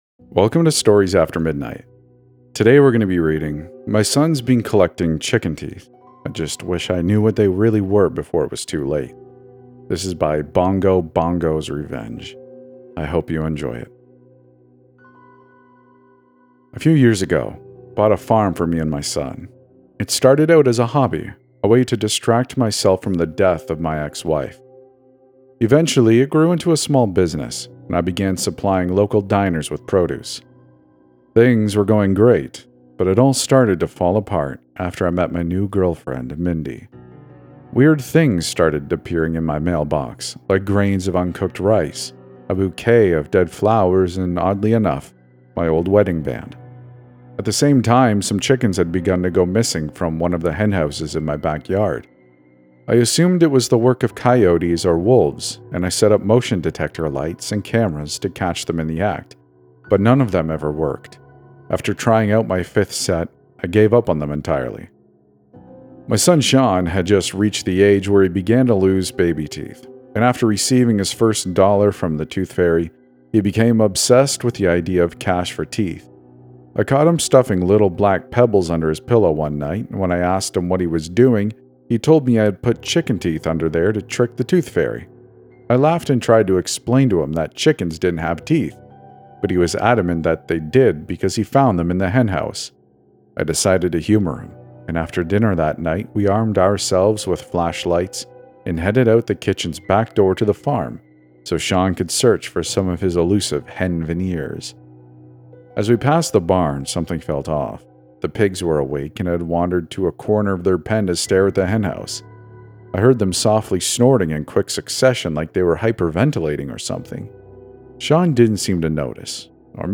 E519 | I'm haunted by my dead wife... | Horror Fiction | Not AI